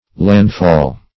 Landfall \Land"fall\, n.